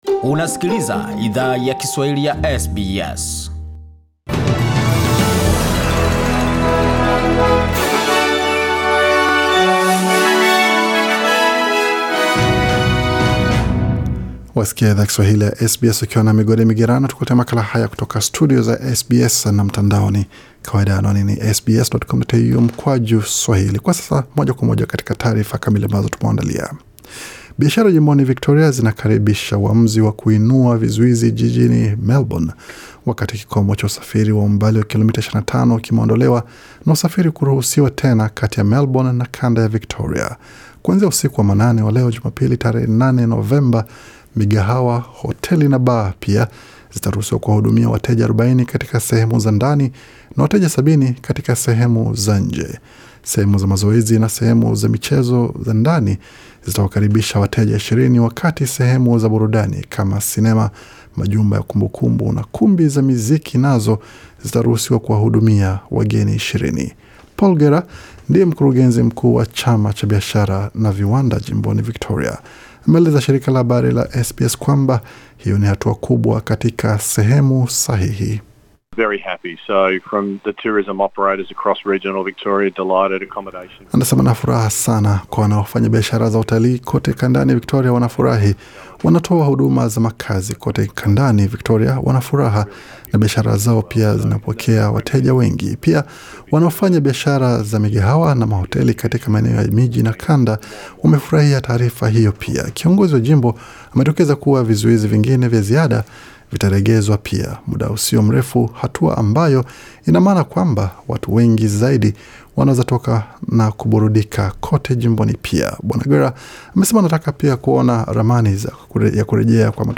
Taarifa ya habari 8 Novemba 2020